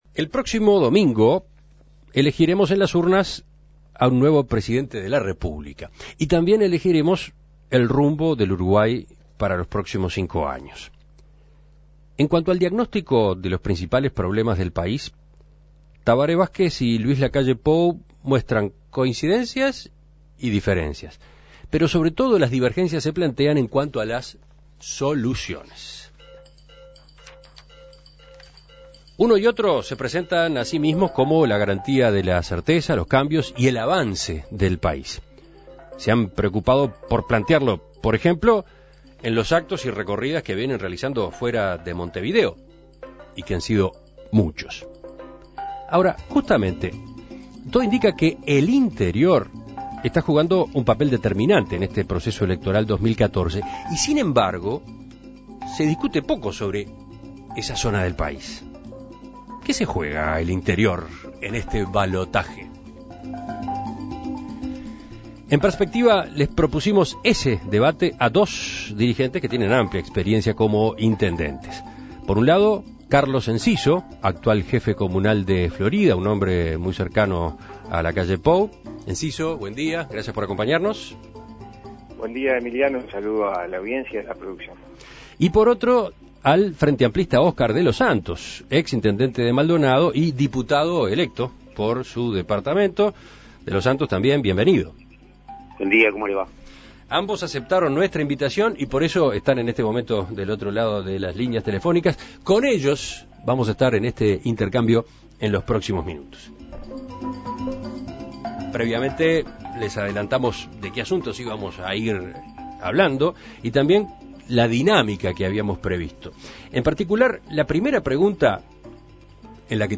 Debate En Perspectiva: El interior también juega sus cartas de cara al balotaje